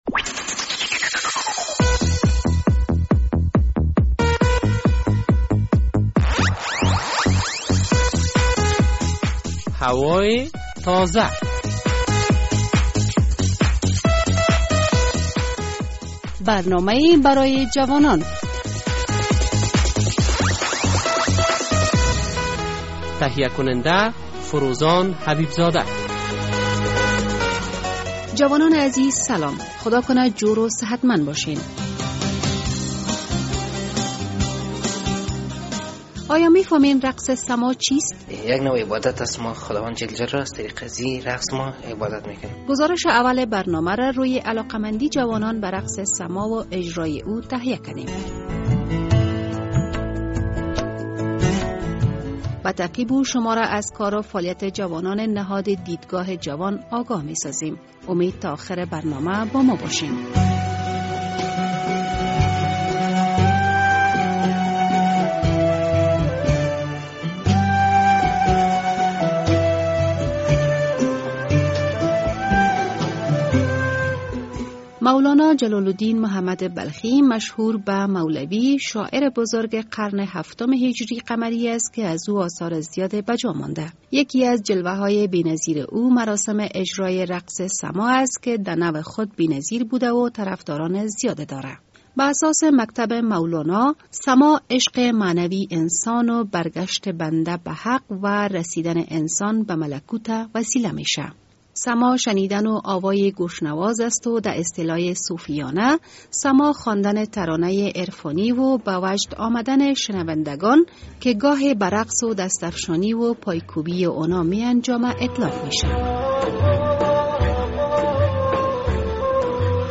رقص سماع توسط جوانان ولایت بلخ!